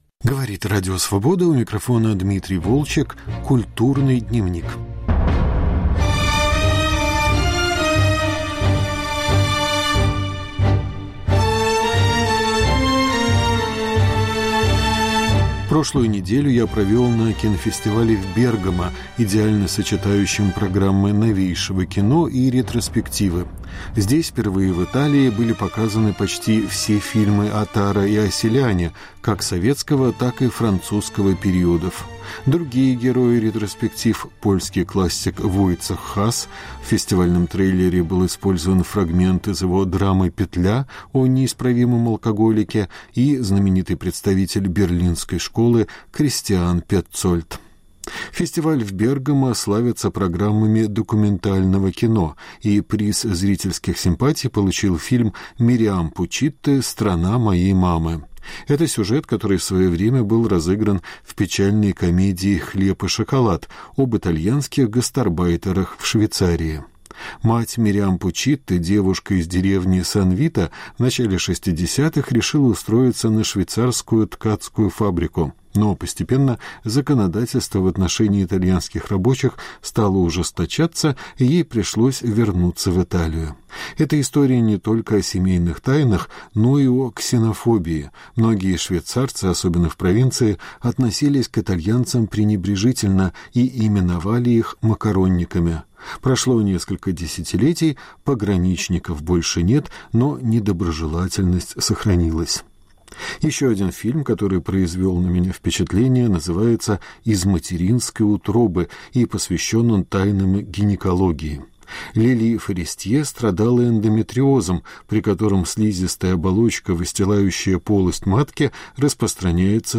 Разговоры на фестивалях в Бергамо и Праге